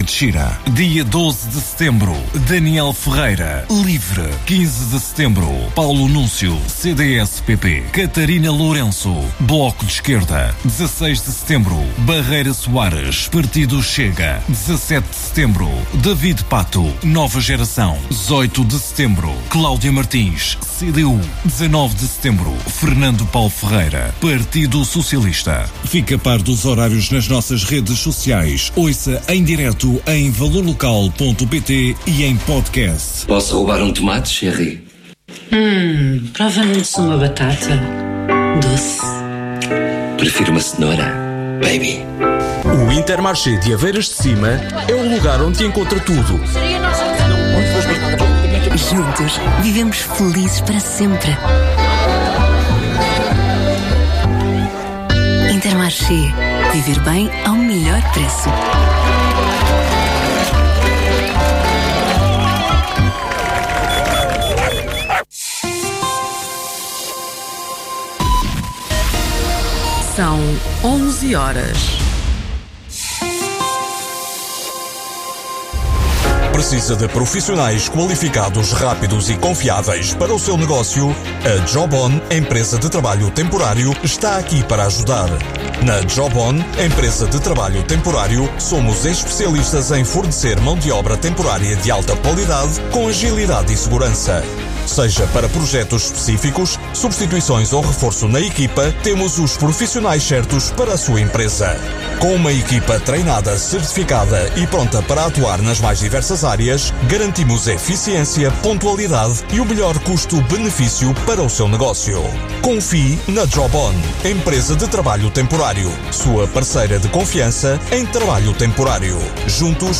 Autárquicas 2025 - Vila Franca de Xira - Entrevista com Barreira Soares - Chega - Jornal e Rádio Valor Local Regional - Grande Lisboa, Ribatejo e Oeste